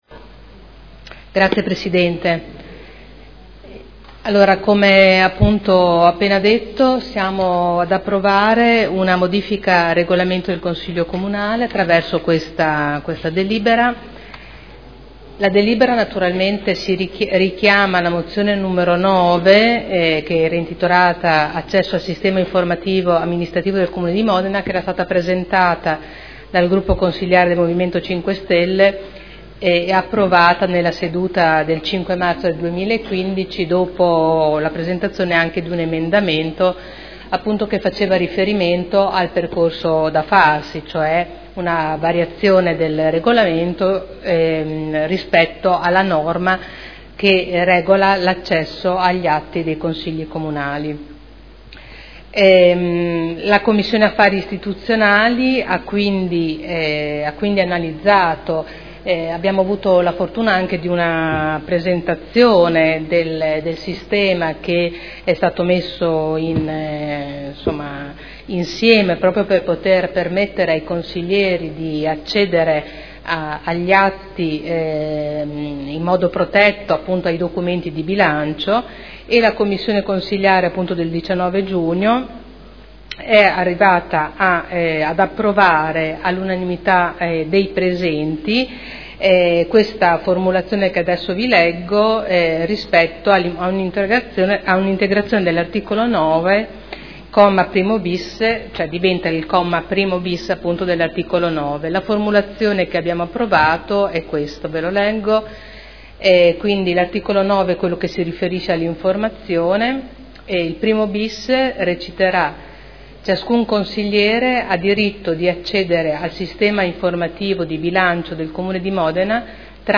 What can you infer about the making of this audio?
Seduta del 09/07/2015 Accesso al sistema informativo di Bilancio del Comune di Modena – Modifiche al Regolamento del Consiglio Comunale